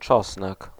Ääntäminen
Ääntäminen France (Île-de-France): IPA: /aj/ Paris: IPA: [aj] Haettu sana löytyi näillä lähdekielillä: ranska Käännös Konteksti Ääninäyte Substantiivit 1. czosnek {m} kasvitiede Suku: m .